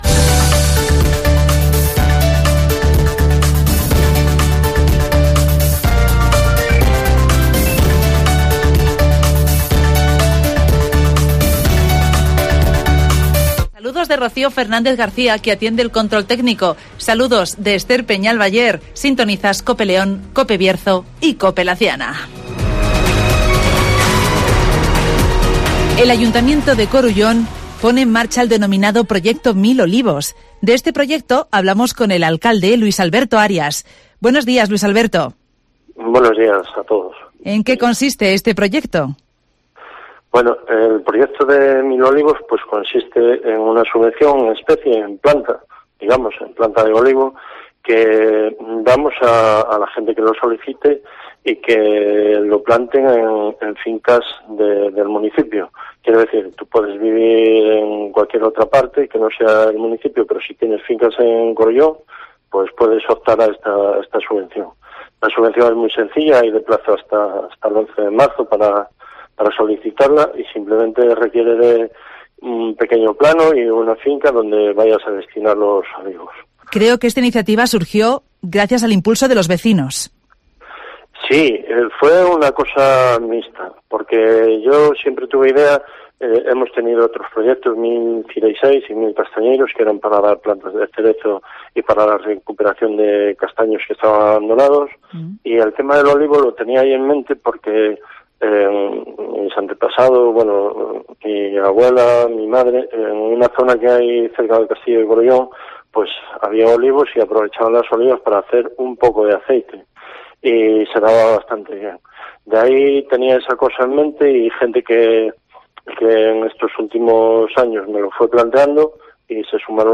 El Ayuntamiento de Corullón ha puesto en marcha el denominado Proyecto Mil Olivos (Entrevista a Luis Alberto, alcalde del municipio